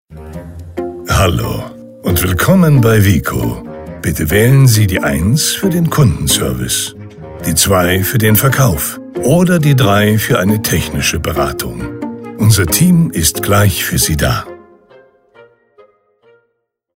Kein Problem! – Rufen Sie doch einfach unseren Kunden WICO an, denn WICO hat seit heute frische 1a-telefonansagen mit der deutschen Synchronstimme von Jürgen Kluckert alias Morgan Freeman erhalten.
IVR Ansage